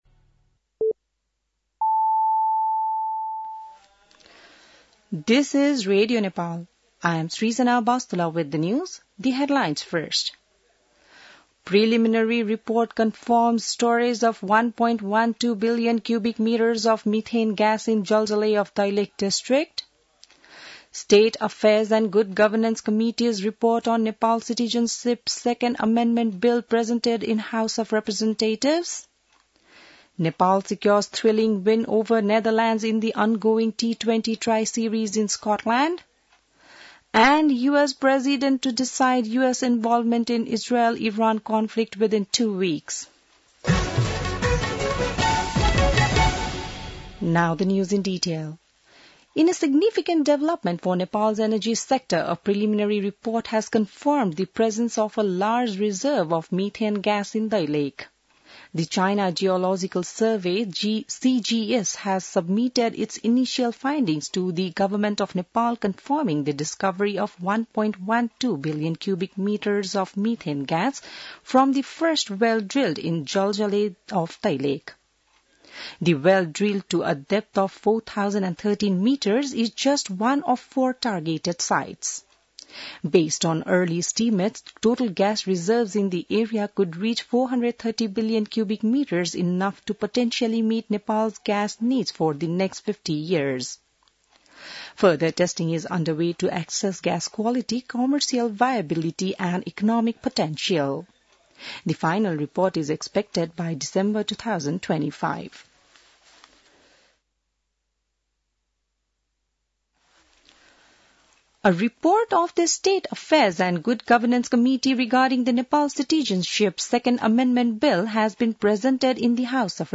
बिहान ८ बजेको अङ्ग्रेजी समाचार : ६ असार , २०८२